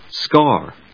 /skάɚ(米国英語), skάː(英国英語)/